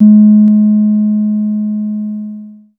Percs
REDD PERC (18).wav